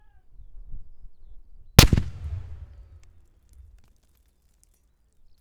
L109A1-HE GRENADES
Here’s some fragmentation grenades being thrown into a stone target area (the background of the photo above), I was able to get the mics pretty close for this and wasn’t afraid about losing any if they were too close.
Olympus LS5 at 20m
Got the LS5 at 20m for a bit and then moved it out to 200m…